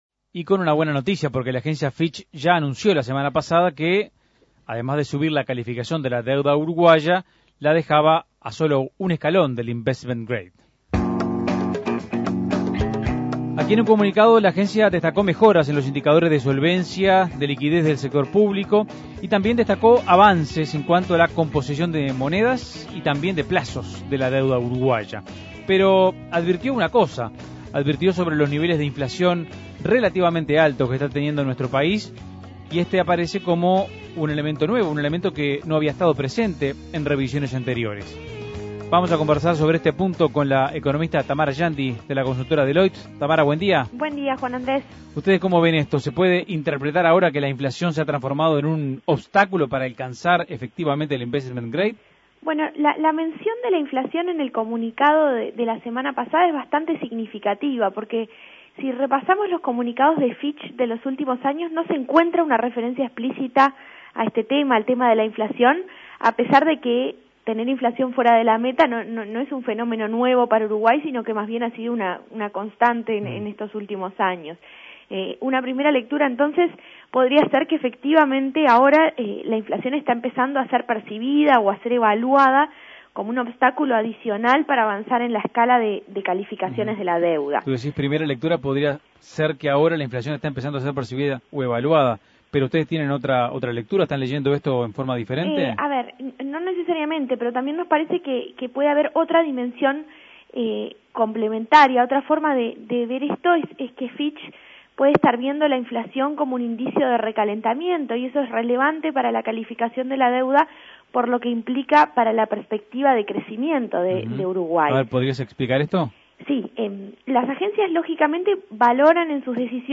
Análisis Económico Fitch Ratings llama la atención sobre la inflación de Uruguay; ¿se trata de un nuevo obstáculo para alcanzar el investment grade?